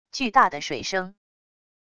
巨大的水声wav音频